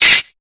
Cri de Nénupiot dans Pokémon Épée et Bouclier.